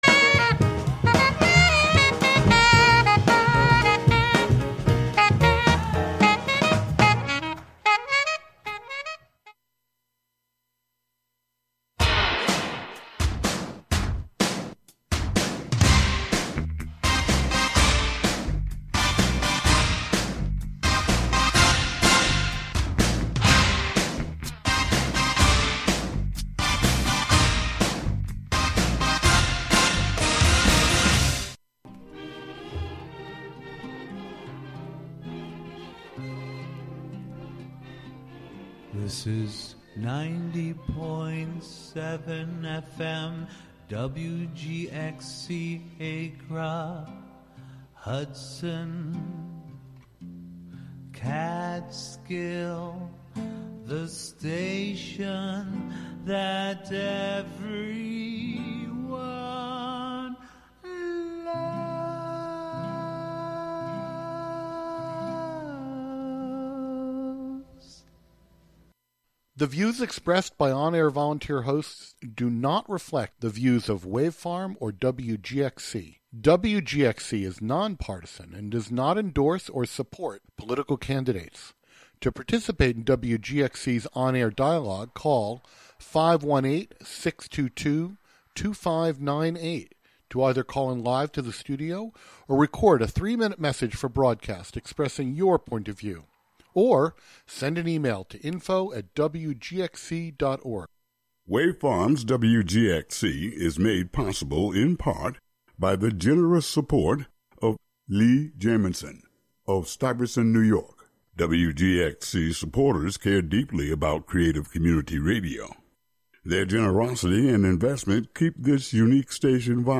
Their tunes will be broadcast live on Wave Farm / WGXC 90.7 fm!
Sounds, songs, and radio art from a variety of contributors. Tune in for something you've never heard before with familiar classics and transmission art mixed in.